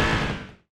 Index of /server/sound/vehicles/sgmcars/buggy
stop.wav